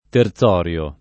[ ter Z0 r L o ]